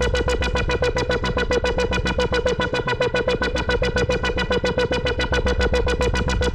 Index of /musicradar/dystopian-drone-samples/Tempo Loops/110bpm
DD_TempoDroneA_110-B.wav